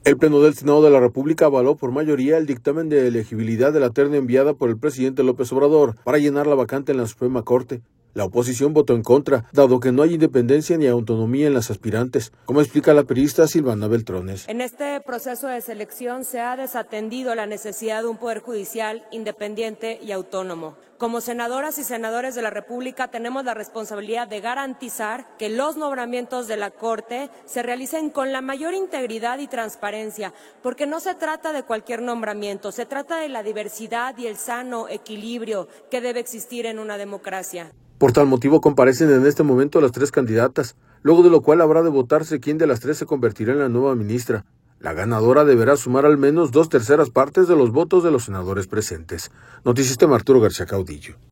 El Pleno del Senado de la República avaló por mayoría el dictamen de elegibilidad de la terna enviada por el presidente López Obrador, para llenar la vacante en la Suprema Corte. La oposición votó en contra, dado que no hay independencia ni autonomía en las aspirantes, como explica la priista Sylvana Beltrones.